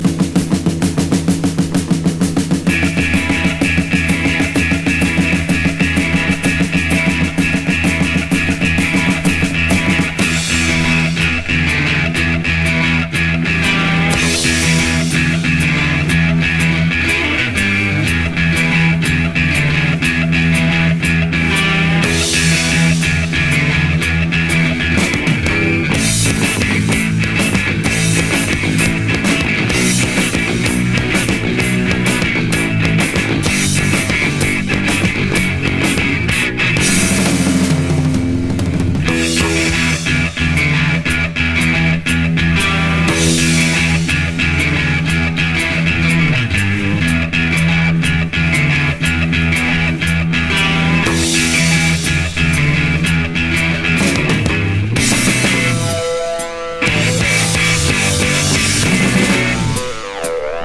’71年クラウトロック傑作。